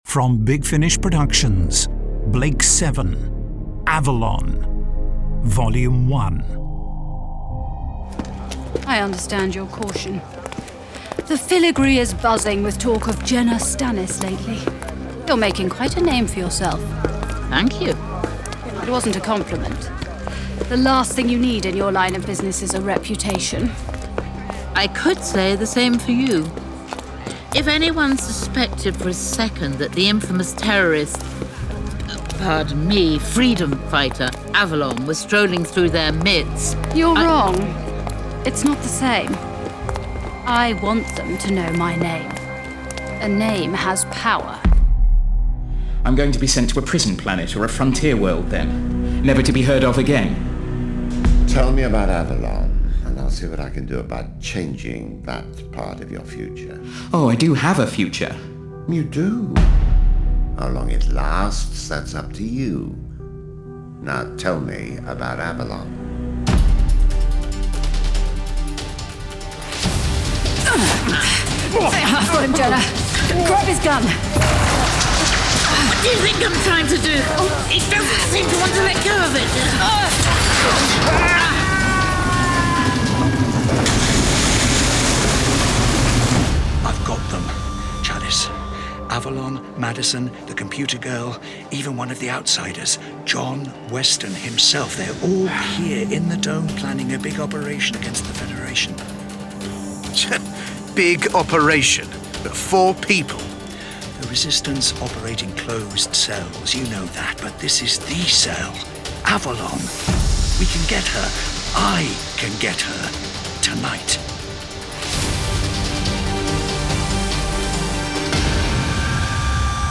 Starring Olivia Poulet Sally Knyvette